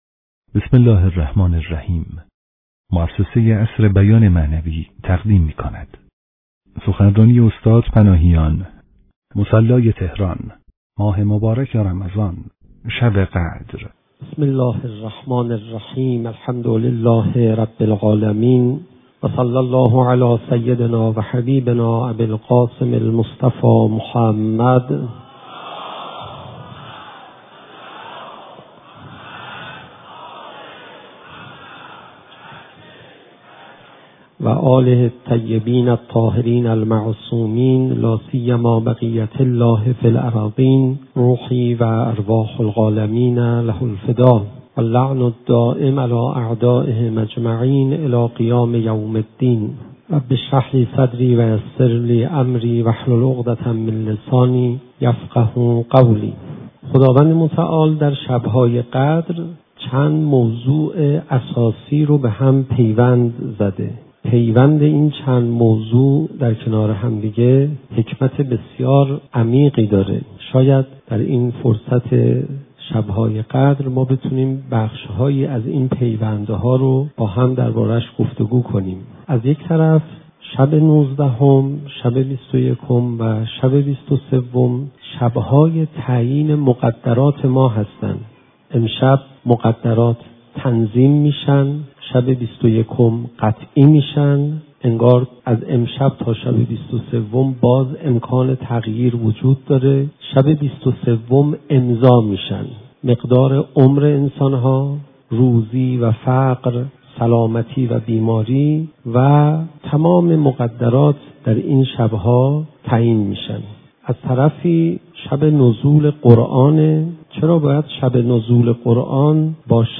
صوت | پیوند مقدرات در شب قدر و مراسم قرآن به سر گرفتن (مصلی بزرگ تهران - شب نوزدهم رمضان 95 - 1 جلسه)
مکان: تهران - مصلی